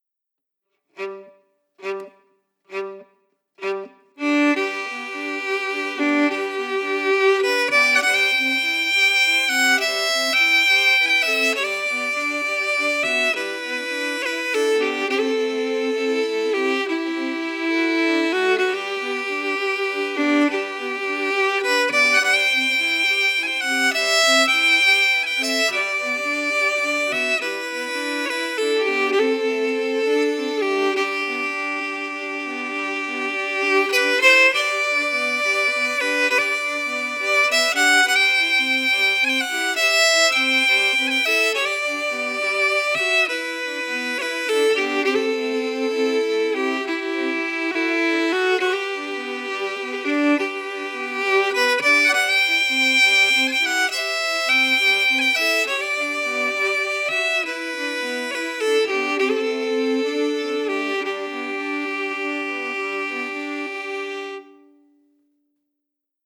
Key: G
Form: Jig
Melody Emphasis
Source: Trad.
Region: Ireland